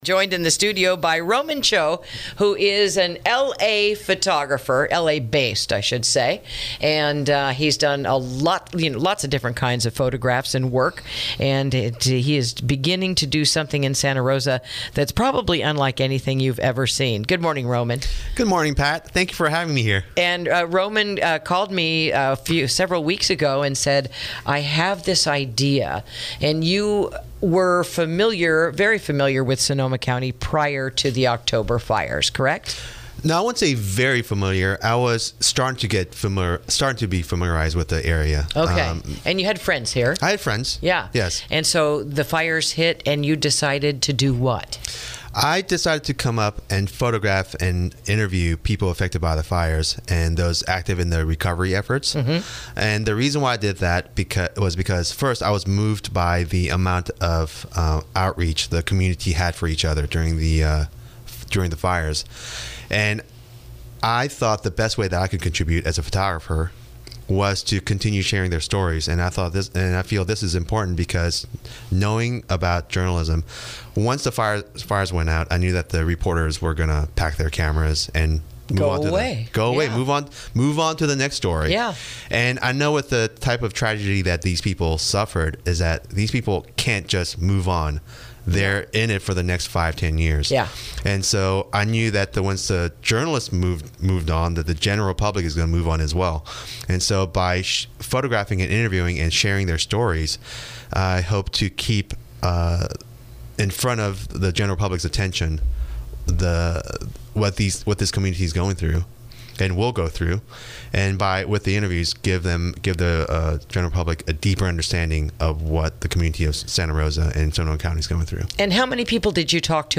INTERVIEW: The "Ashes Fell Like Snow" Exhibit Starting Thursday Evening in Courthouse Square | KSRO 103.5FM 96.9FM & 1350AM